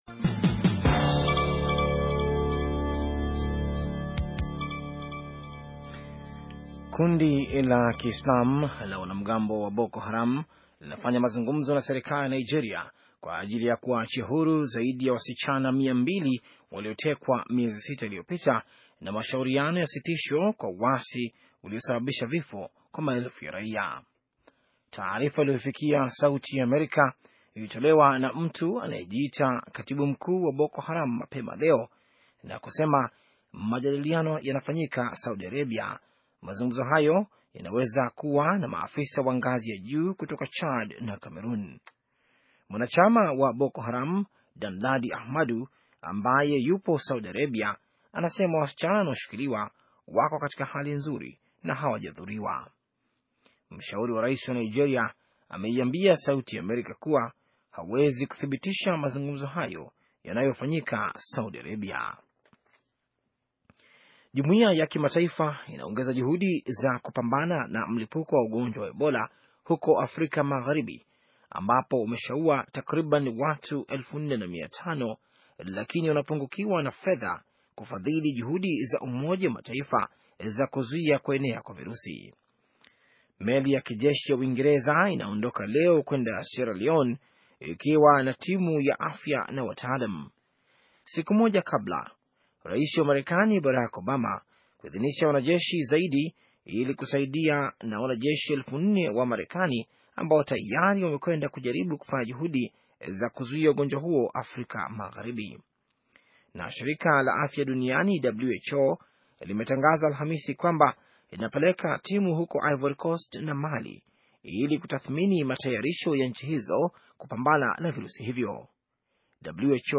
Taarifa ya habari - 6:13